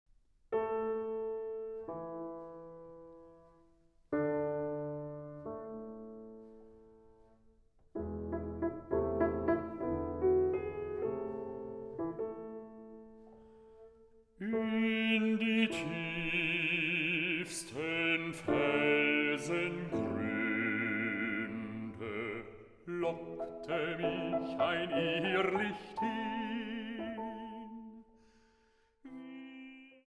Bariton
Klavier